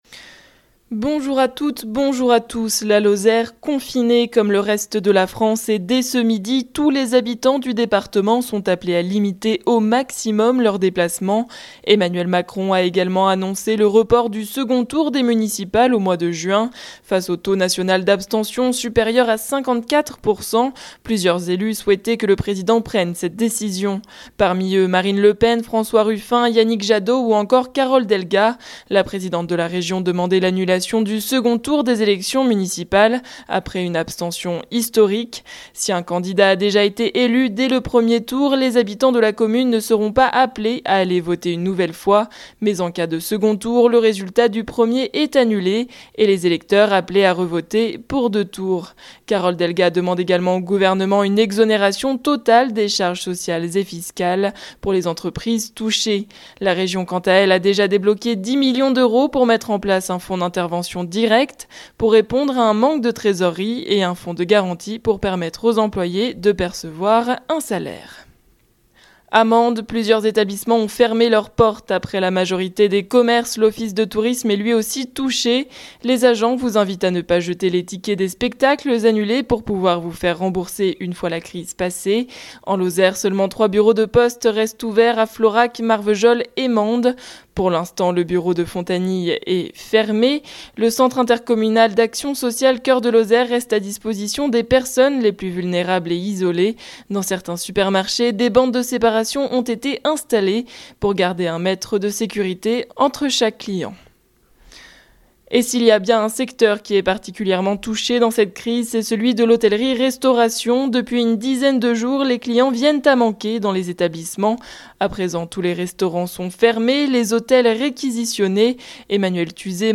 Le journal du mardi 17 mars 2020
Les informations locales